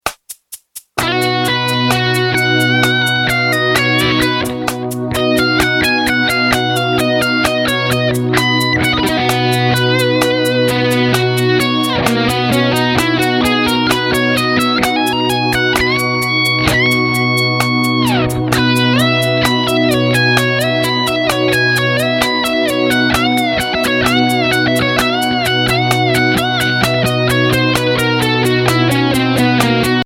Voicing: Guitar Rif